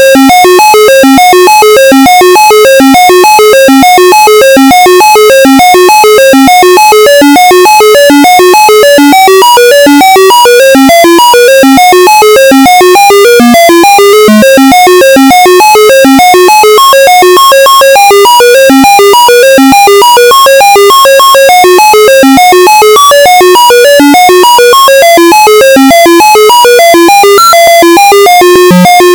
Theme tune